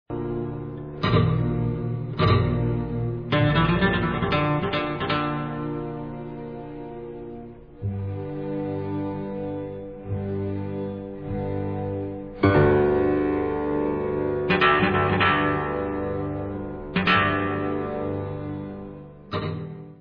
Spaghetti western magic from the meastro